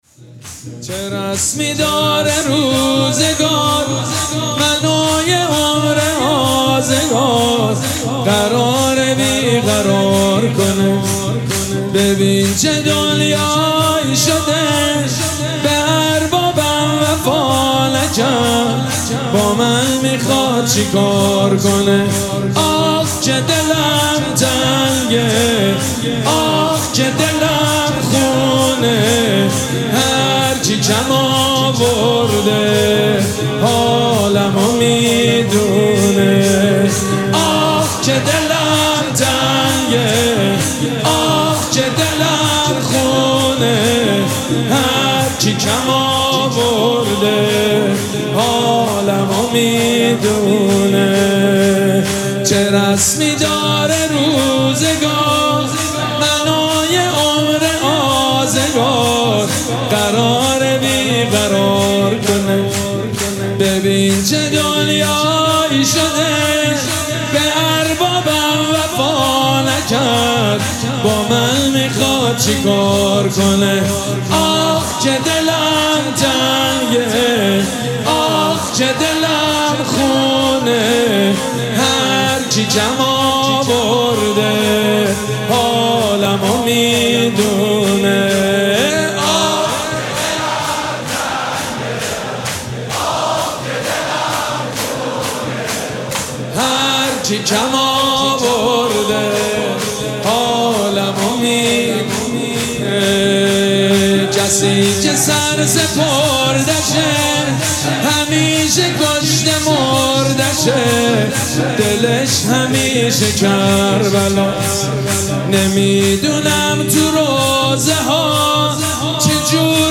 مراسم عزاداری شام غریبان محرم الحرام ۱۴۴۷
شور
مداح
حاج سید مجید بنی فاطمه